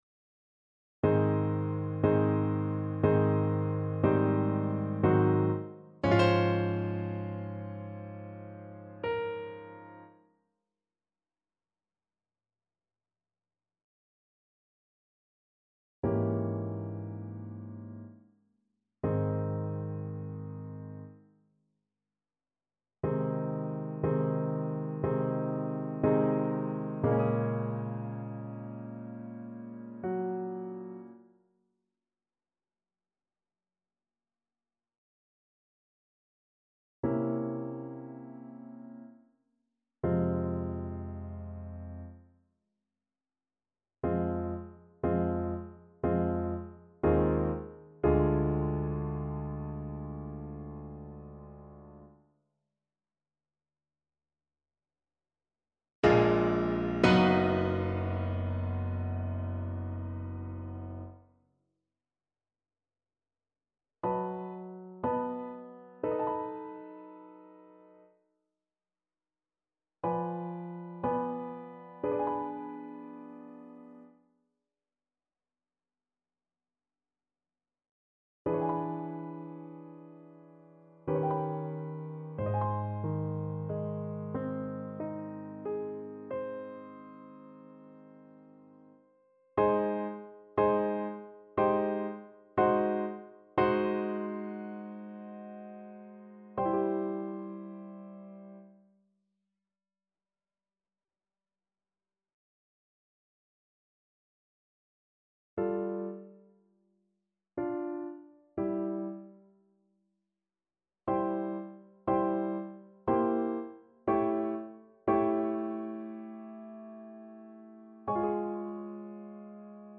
~ = 60 Langsam, leidenschaftlich
Classical (View more Classical Voice Music)